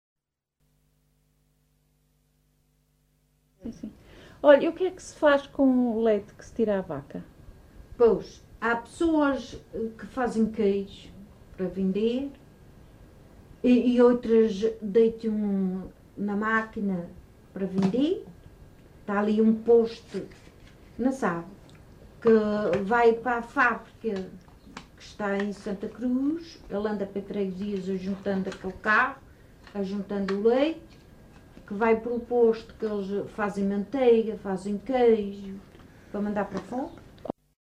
LocalidadeFajãzinha (Lajes das Flores, Horta)